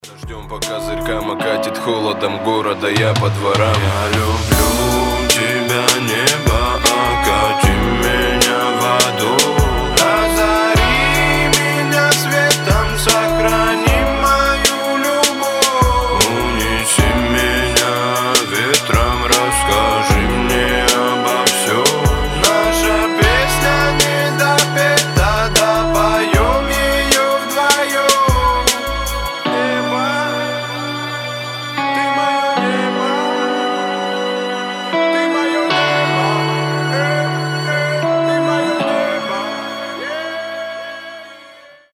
Лирика
Медленные